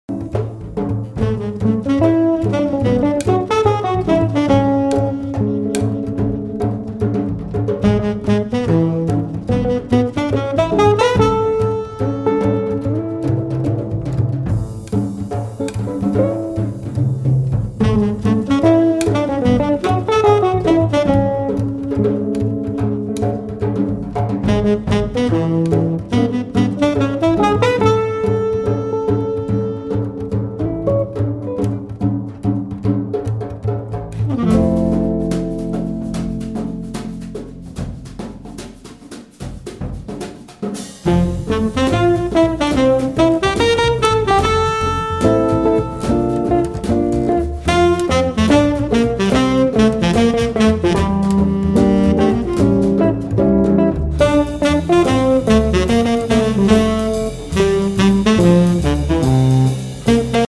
guitar
double bass
soprano and tenor sax
drums